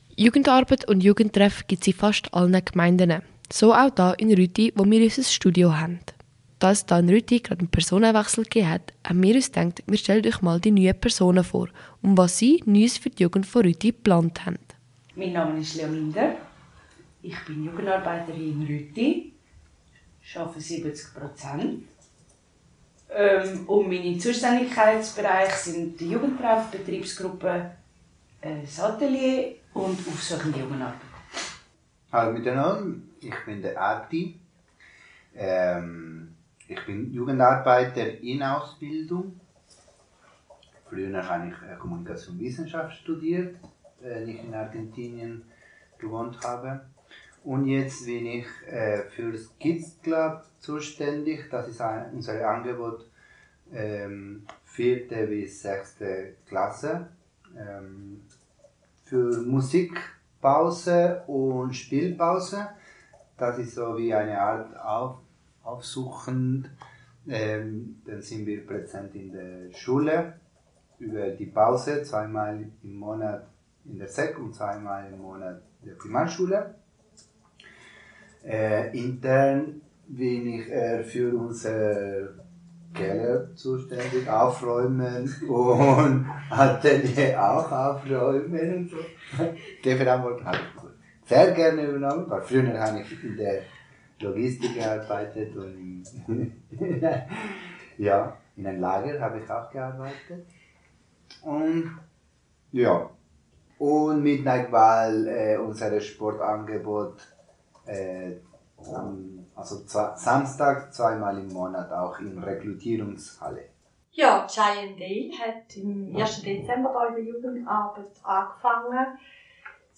In einem Radiobeitrag stellen wir die neuen Mitarbeiterinnen vor und geben Einblicke in die Angebote, die auf die nächste Generation zugeschnitten sind.